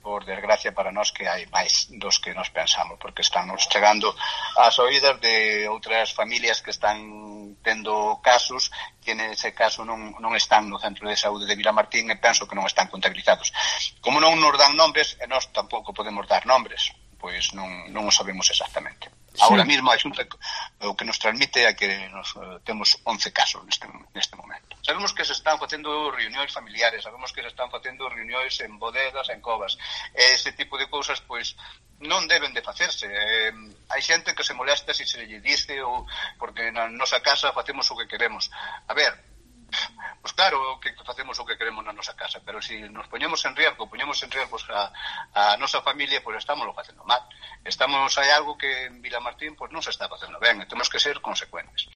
Declaraciones del alcalde, Enrique Álvarez, sobre los casos de COVID-19 en Vilamartín de Valdeorras